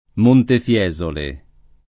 Montefiesole [ montef L$@ ole ]